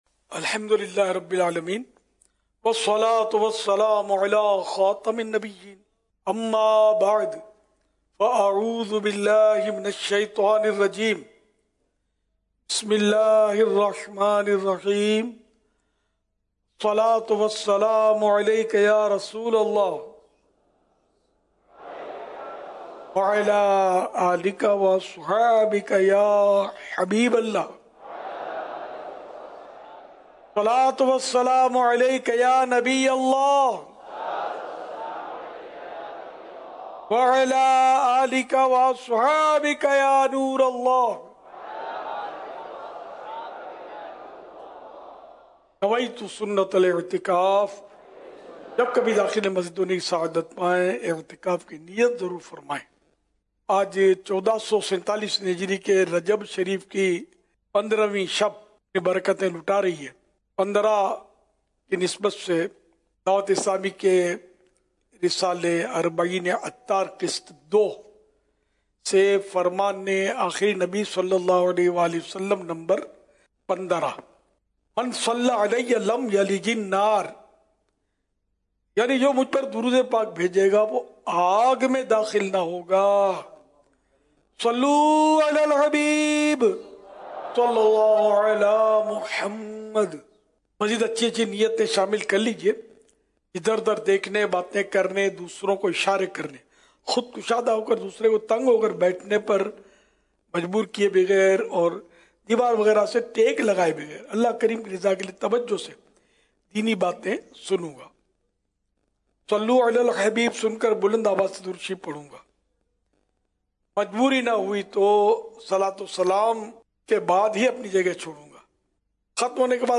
محفلِ نعت بسلسلہ عرس امام جعفر صادق رحمۃ اللہ علیہ Time Duration